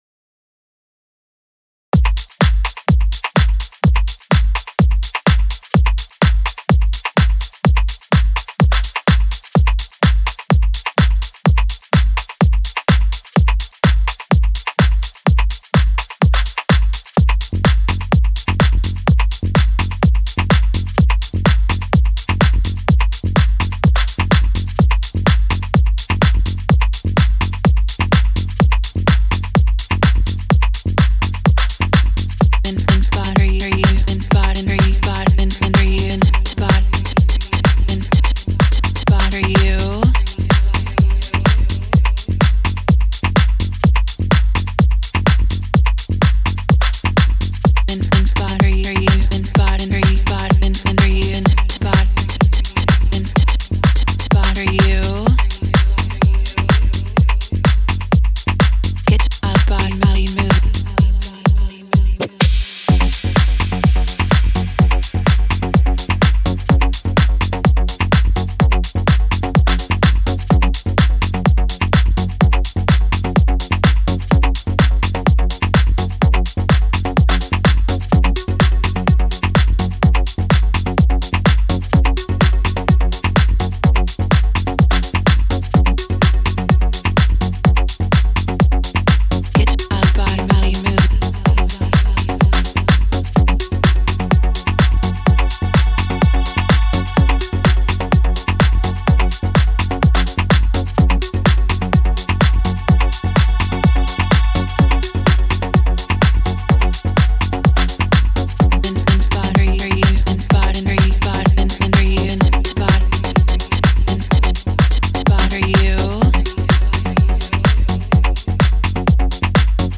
前回同様、素人感満載のトラックではありますが、ぜひ大音量で聴いていただければ嬉しいです。 ・ Deephouse002 (Short ver.)